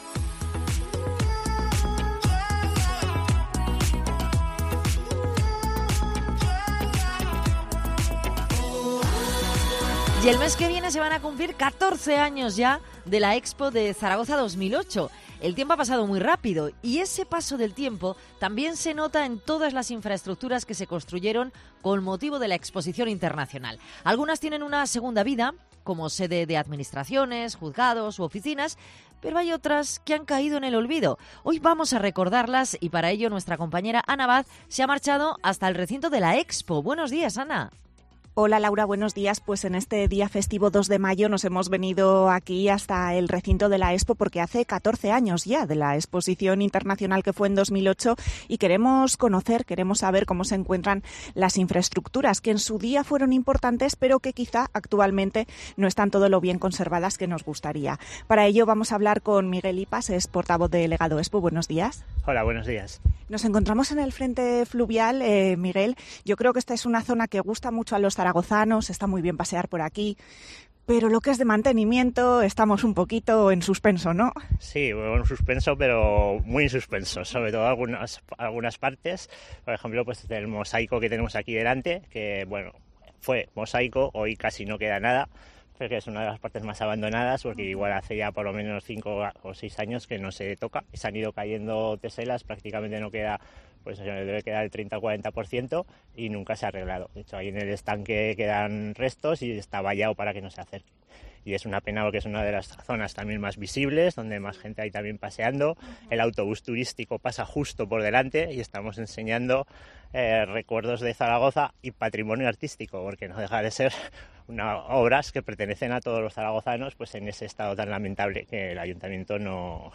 Recorremos el recinto Expo: ¿Cómo se encuentra 14 años después?